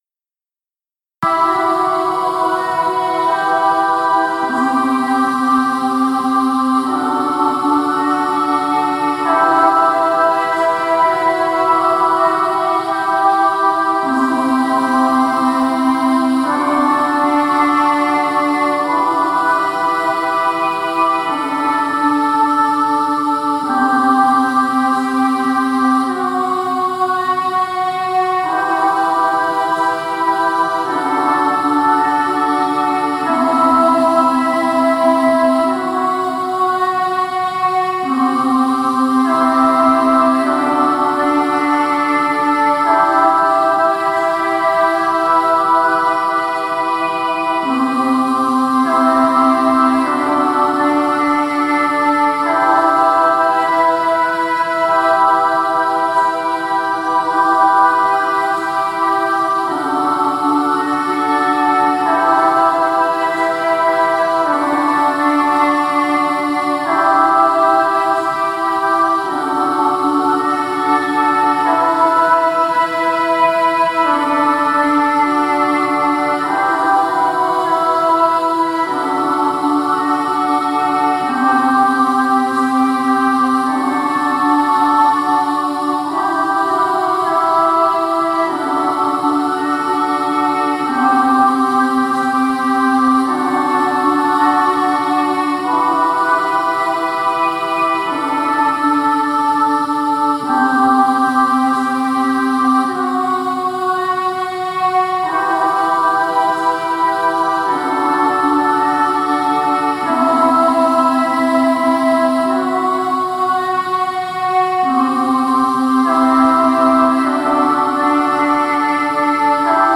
Angel-Choir-Heaven.mp3